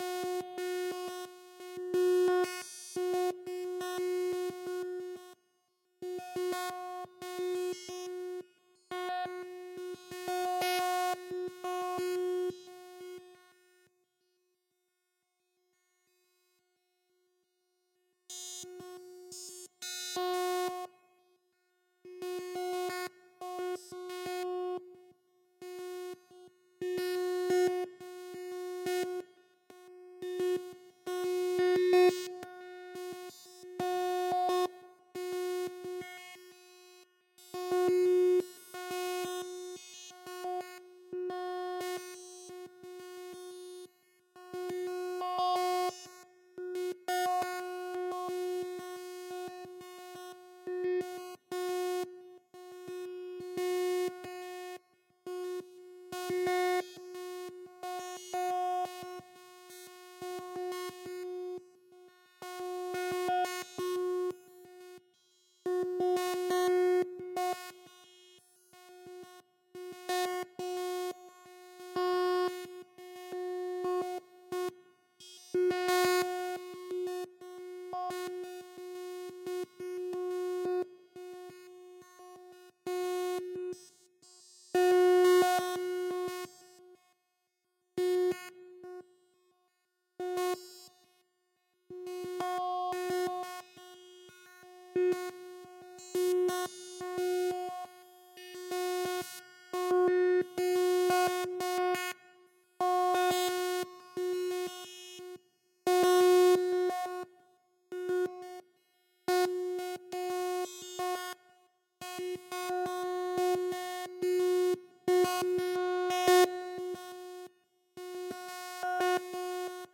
Sermons | The Assembly Heber Springs